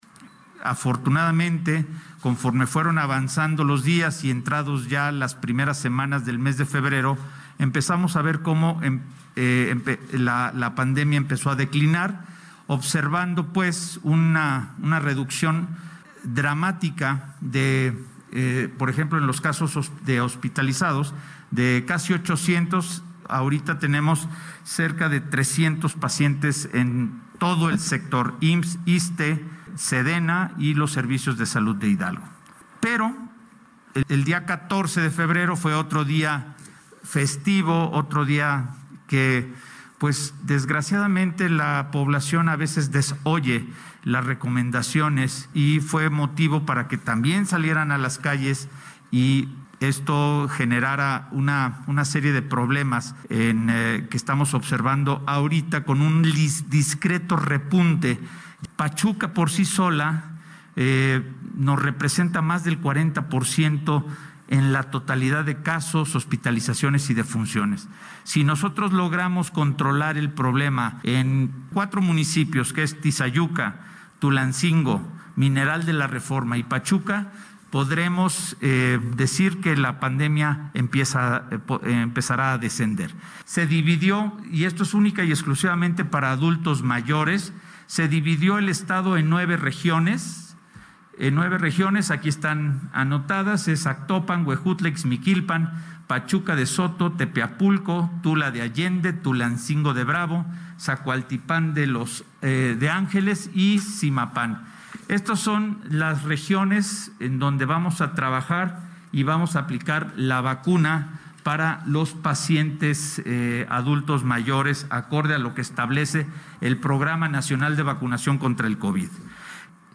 Pachuca, Hgo., a 24 de febrero de 2021.- Hasta el momento en Hidalgo, se han vacunado contra COVID19 a 21 mil 770 adultos mayores de los municipios donde arrancó el Plan de Vacunación para este sector (Villa de Tezontepec, Huejutla, Atlapexco y Tolcayuca), cifra a la que en próximas horas se sumarán las 2 mil 925 dosis destinadas a adultos mayores de la región de Atitalaquia, esto de acuerdo a lo calendarizado por el Gobierno Federal para el Estado de Hidalgo, así lo expuso en su conferencia de prensa semanal el titular de la Secretaría de Salud Estatal (SSH), Alejandro Efraín Benítez Herrera.